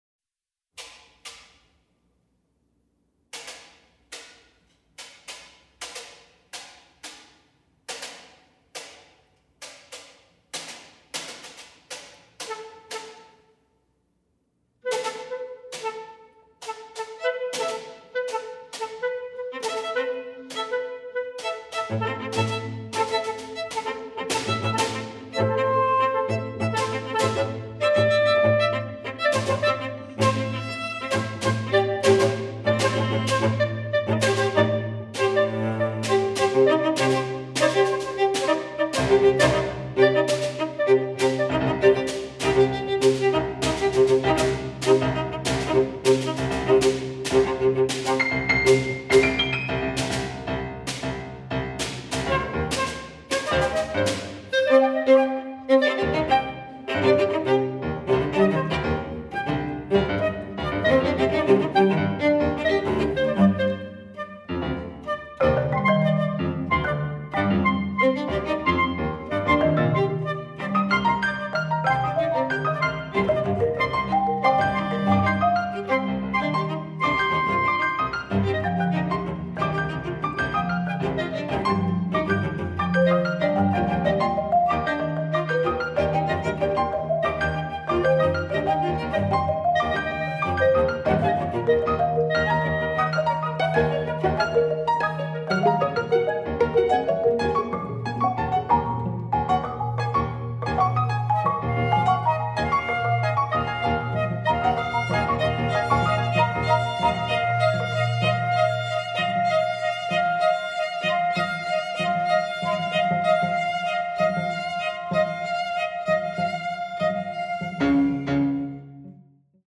flute
clarinet
violin
viola
cello
percussion